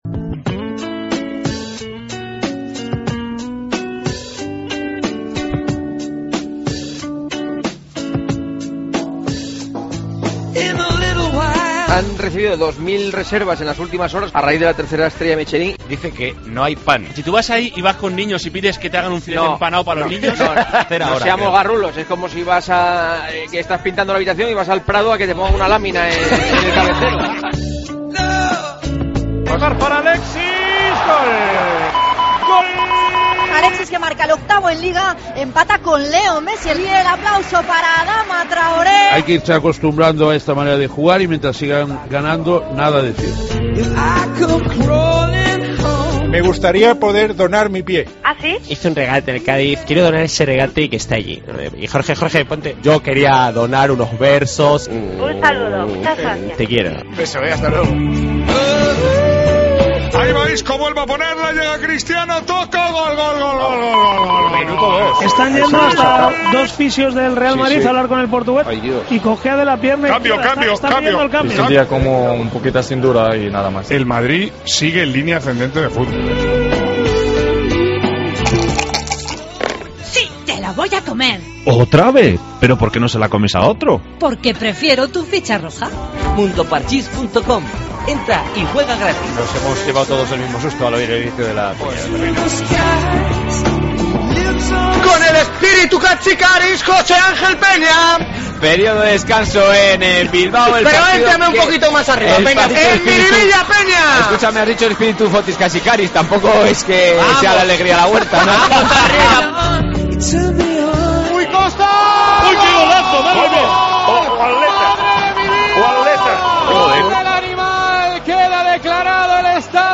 Hablamos de restaurantes con 3 estrellas Michelín, llamamos al Museo de Atapuerca, escuchamos nuevas cuñas curiosas...
Con Paco González, Manolo Lama y Juanma Castaño